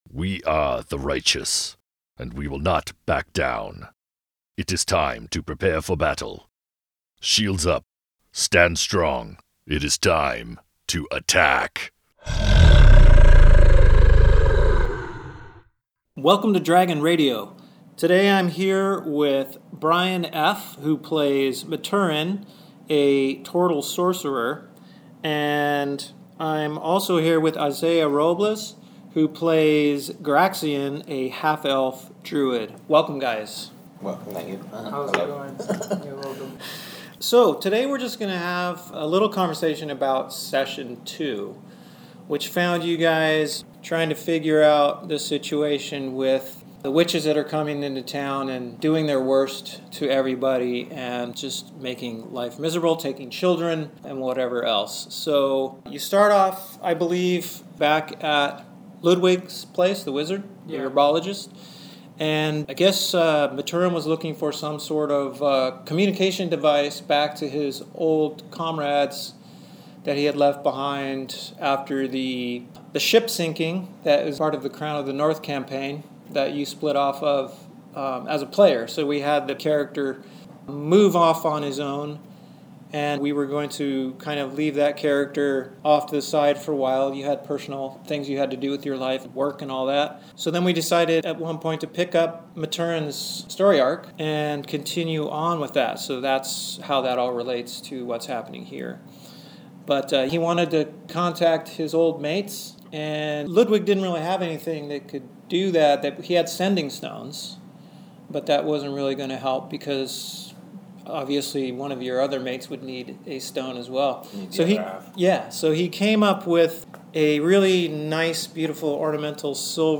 Recorded Via: Dictaphone App on an iPad Mini.